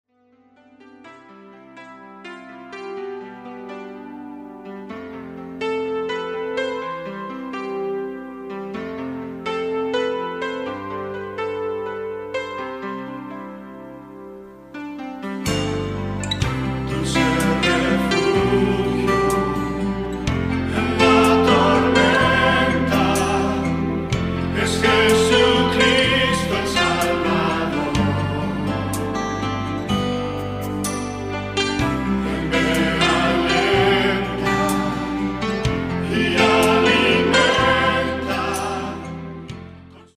Muestras de las pistas
coros